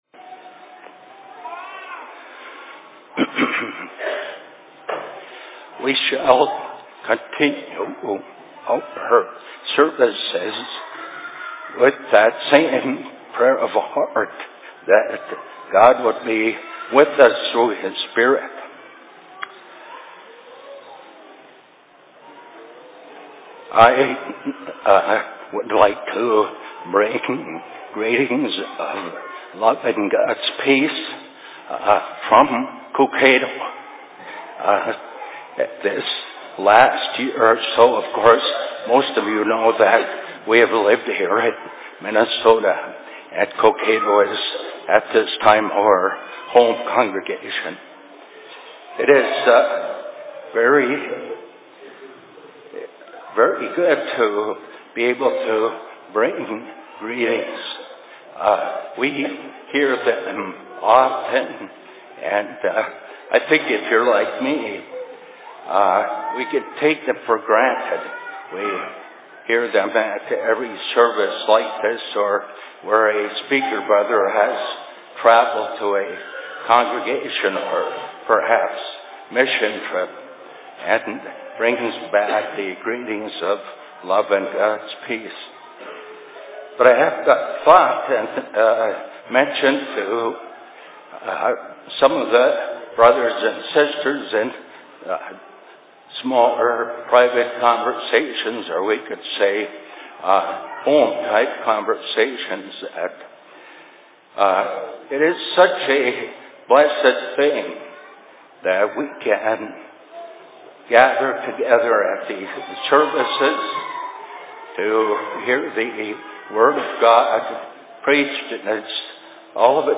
Winter Services/Sermon in Rockford 16.03.2018
Location: LLC Rockford